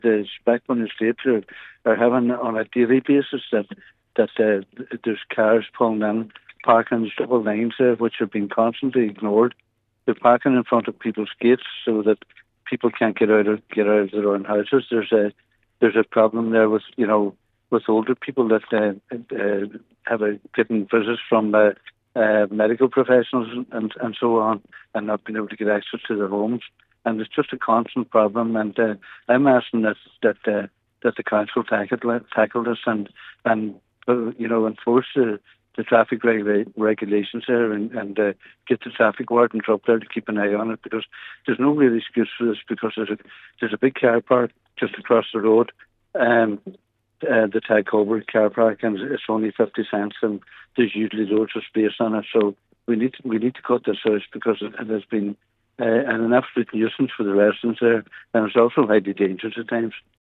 Councillor Jimmy Kavanagh says access for health care staff has also proven difficult on a number of occasions when needing to access properties.
He says parking in the estate poses a huge danger: